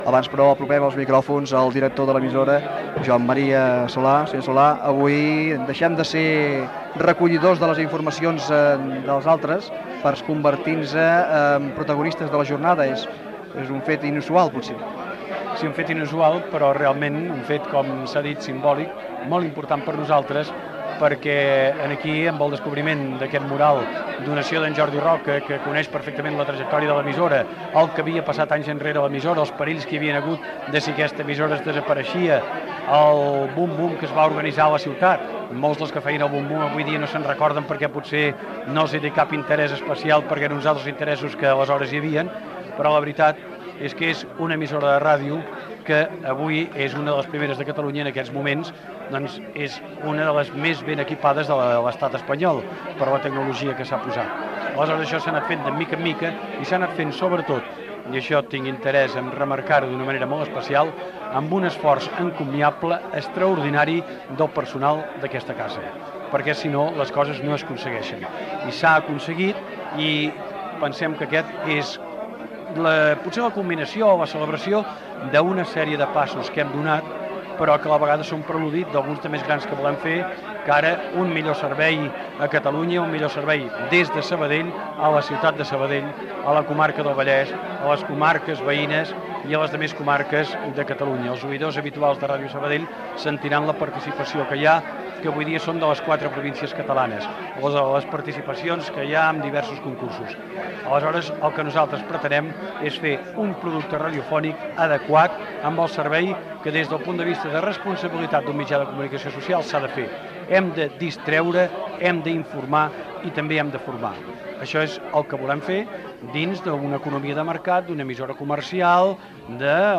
Edició especial amb motiu de la inauguració de la remodelació dels estudis de Ràdio Sabadell.
Entreteniment